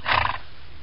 PixelPerfectionCE/assets/minecraft/sounds/mob/horse/breathe2.ogg at mc116
breathe2.ogg